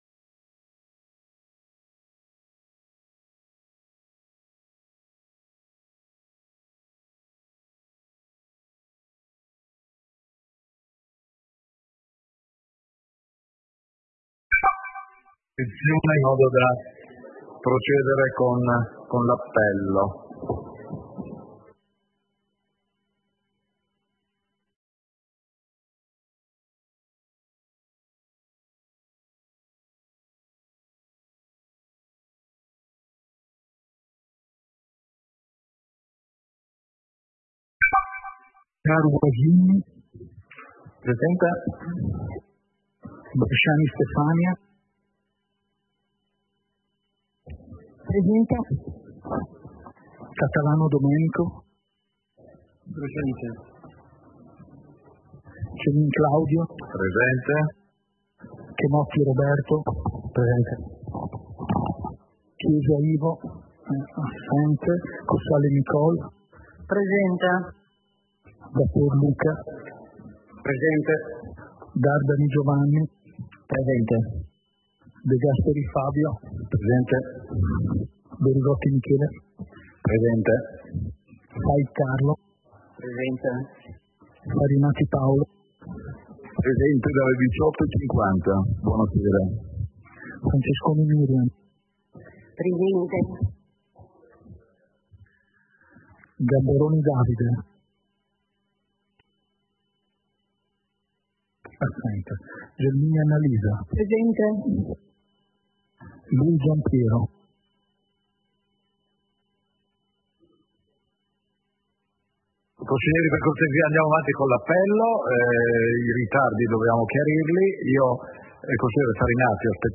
Seduta del consiglio comunale - 23 settembre 2025